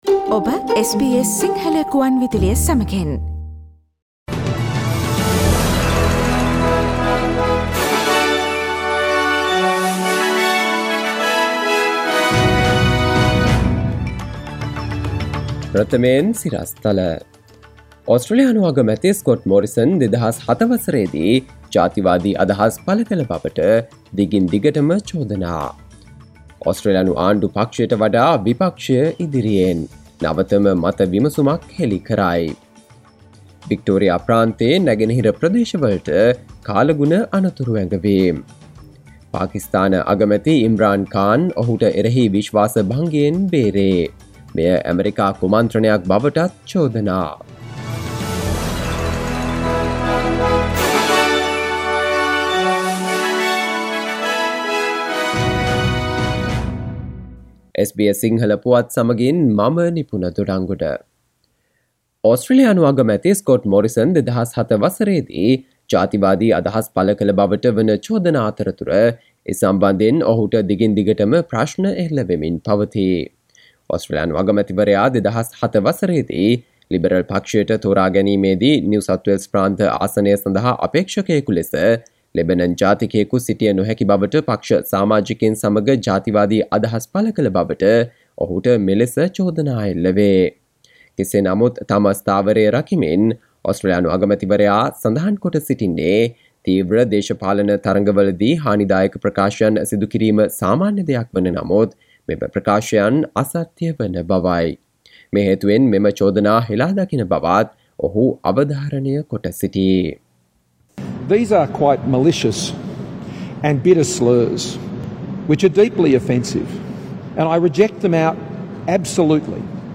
සවන්දෙන්න 2022 අප්‍රේල් 04 වන සඳුදා SBS සිංහල ගුවන්විදුලියේ ප්‍රවෘත්ති ප්‍රකාශයට...